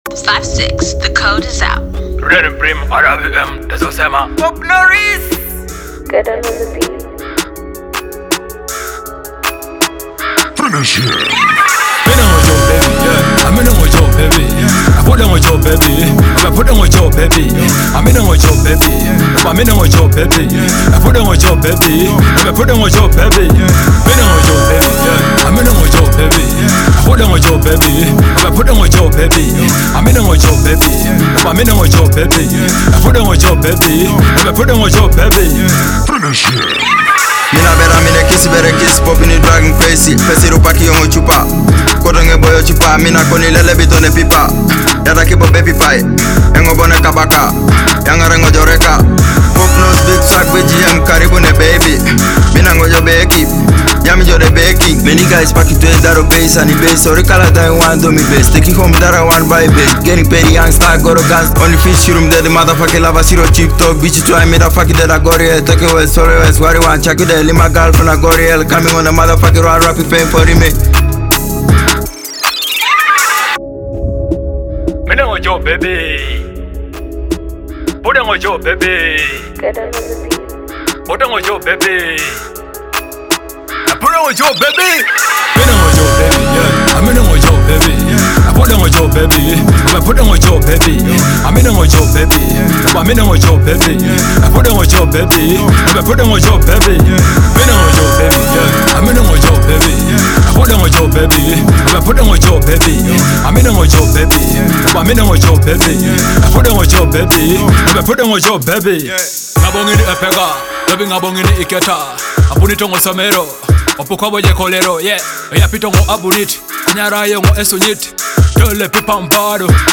a catchy blend of soulful vocals and upbeat rhythms.